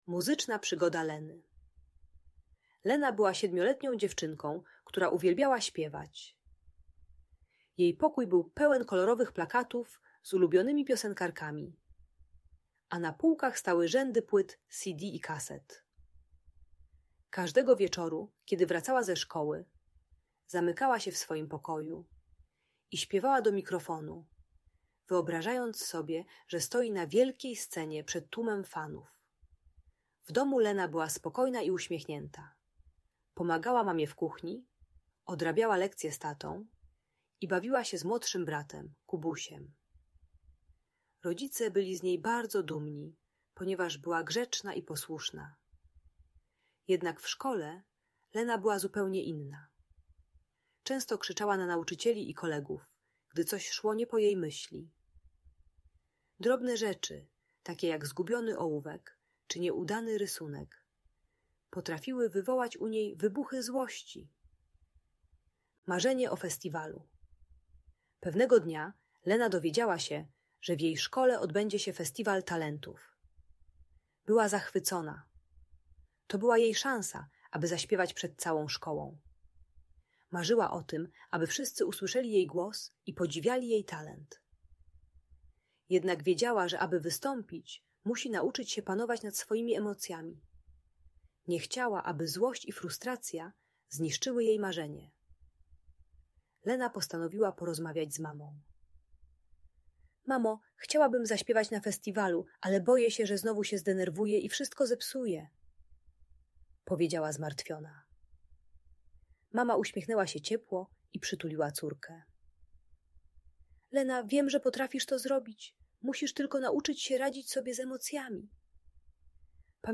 Muzyczna Przygoda Leny - Bunt i wybuchy złości | Audiobajka